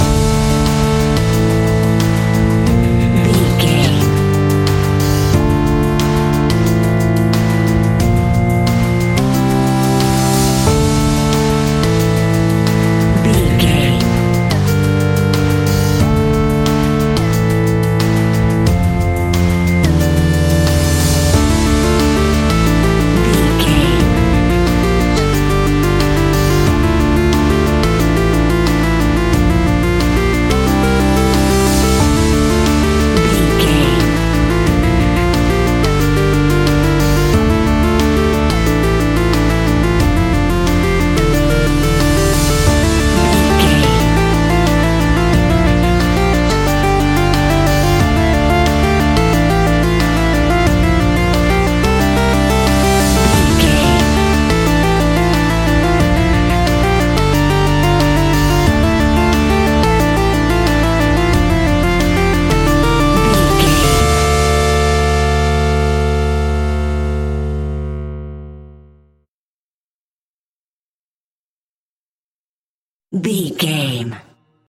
Aeolian/Minor
tension
ominous
dark
eerie
drums
synthesiser
ticking
electronic music